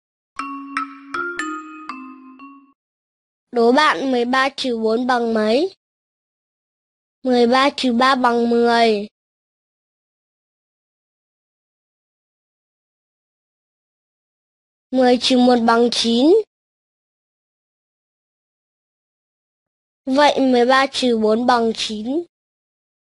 Sách nói | Toán 2 - Trừ bằng cách làm tròn mười